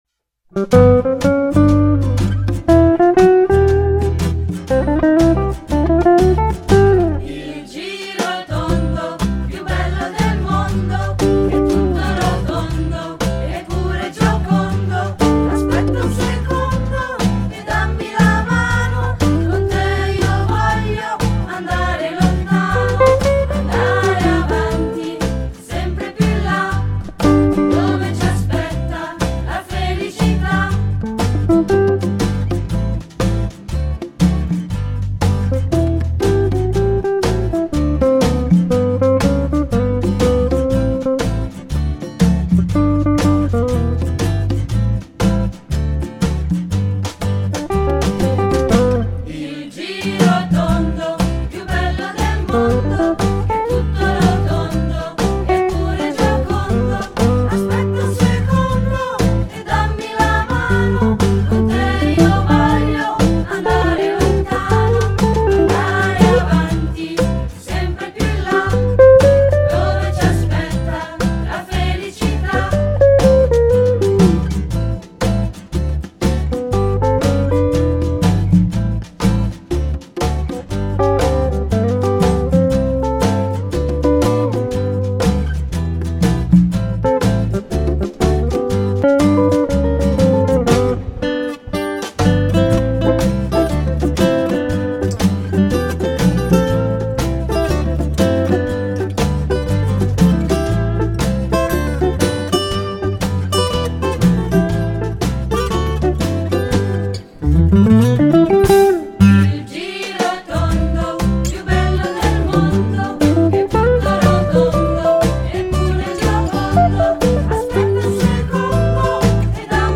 Ascolta la base musicale